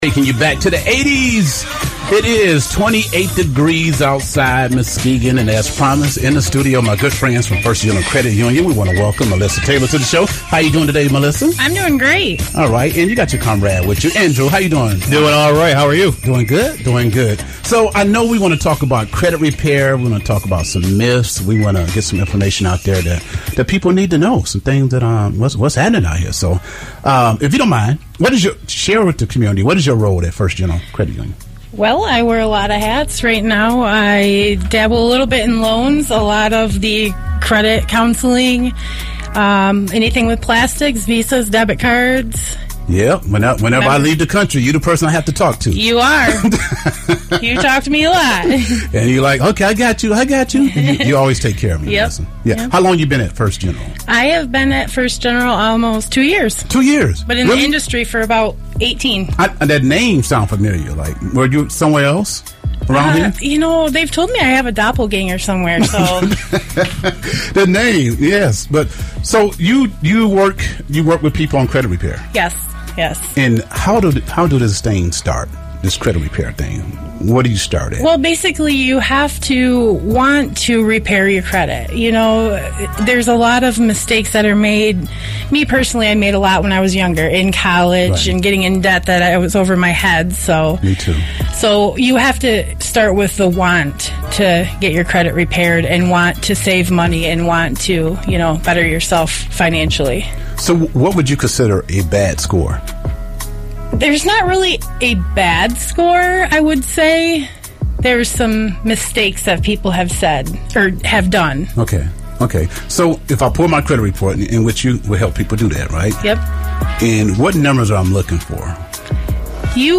Some of the great staff from First General Credit Union stopped by the station to educate the community of the ABC's of Credit.